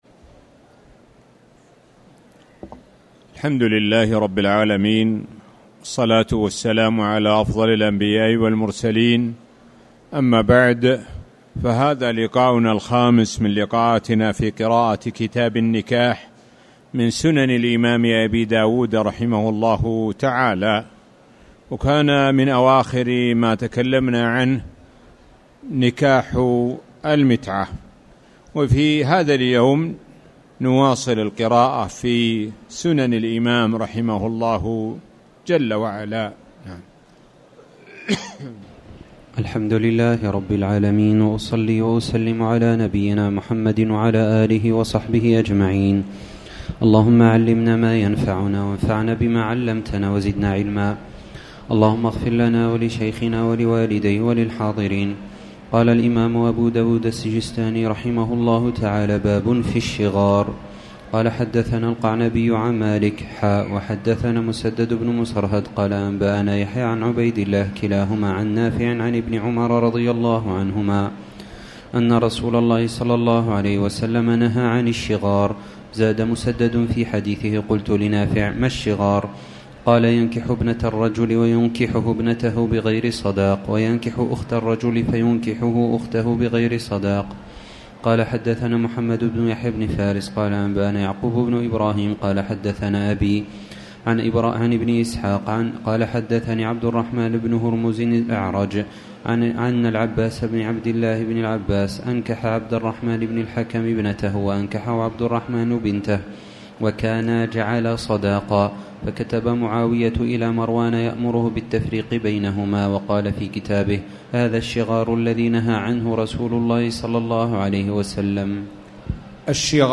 تاريخ النشر ١٩ شوال ١٤٣٨ هـ المكان: المسجد الحرام الشيخ: معالي الشيخ د. سعد بن ناصر الشثري معالي الشيخ د. سعد بن ناصر الشثري باب الشغار